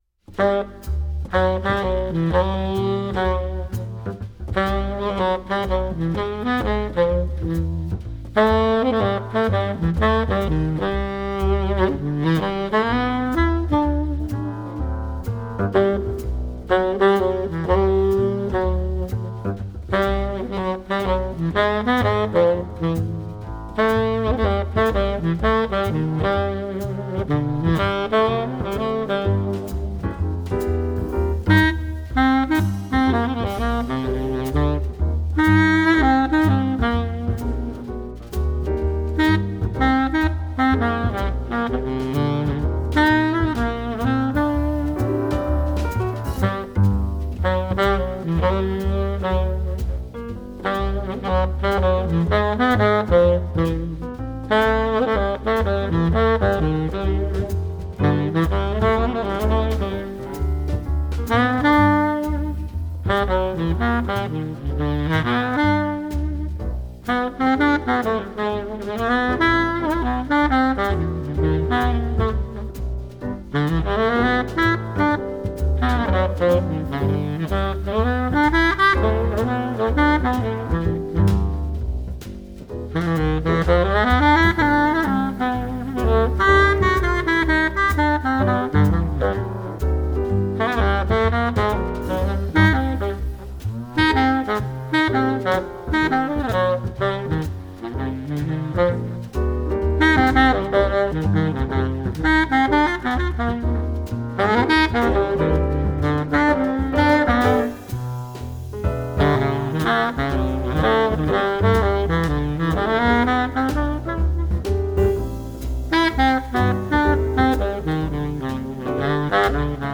This is what a baritone sax sounds like.